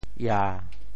潮州 ia7 文